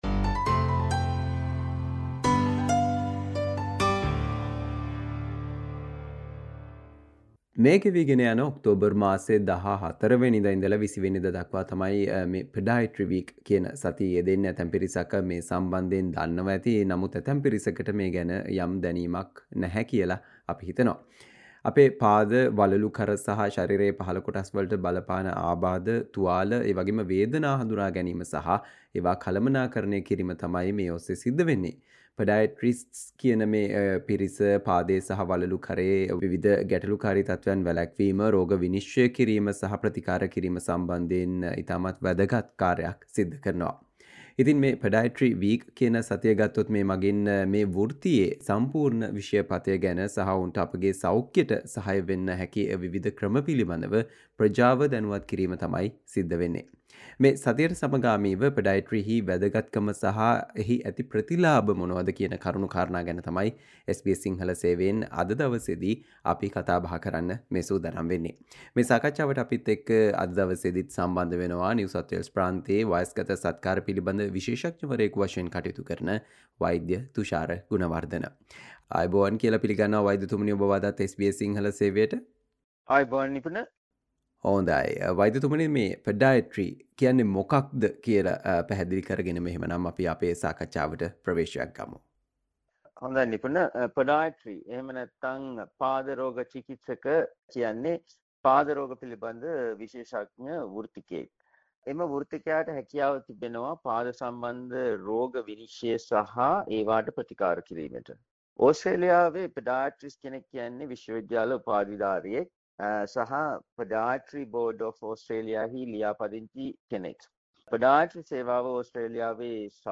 Listen to the SBS Sinhala discussion on the importance and benefits of Podiatry, In conjunction with the Podiatry Week from 14th to 20th October.